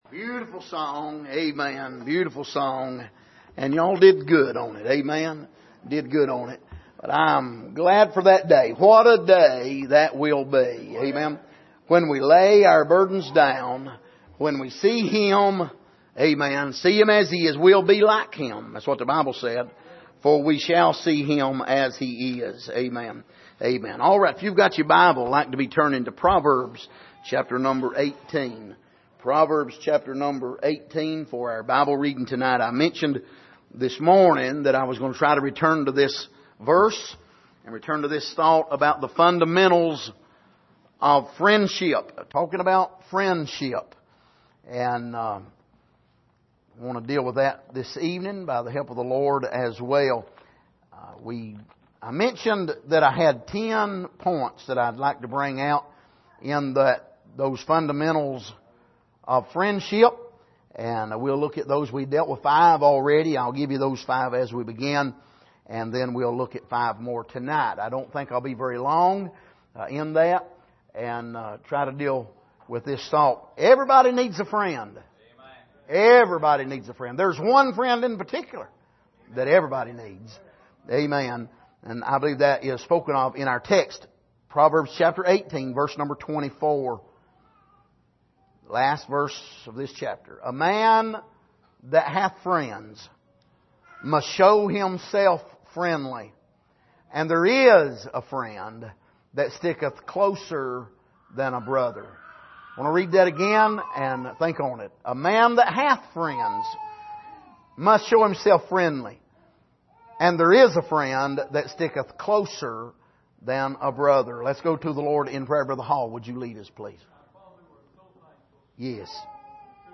Passage: Proverbs 18:24 Service: Sunday Evening